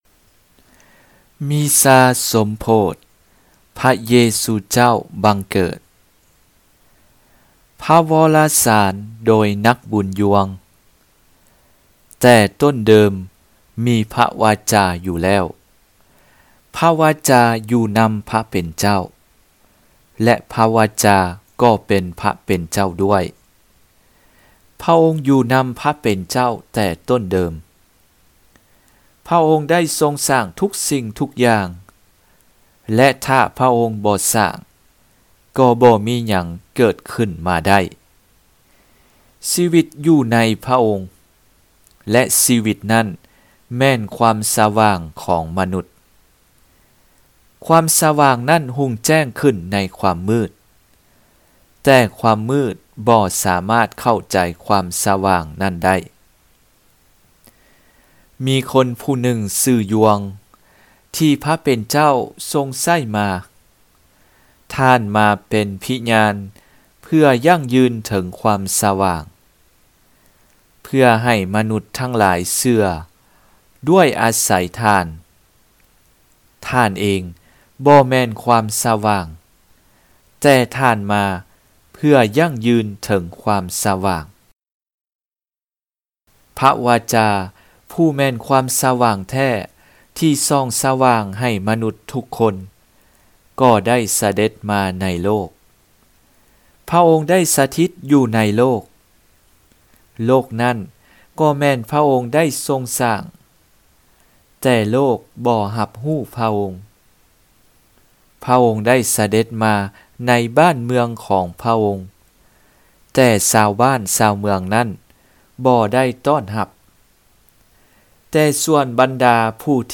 Homelie_jour_Noel.mp3